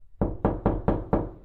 3d-kapi-tiklama-sesi.mp3